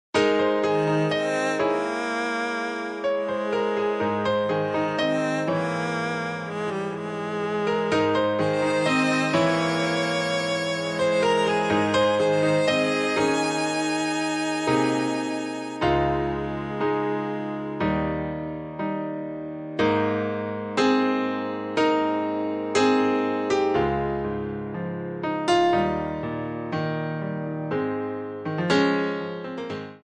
D
MPEG 1 Layer 3 (Stereo)
Backing track Karaoke
Pop, Musical/Film/TV, 1990s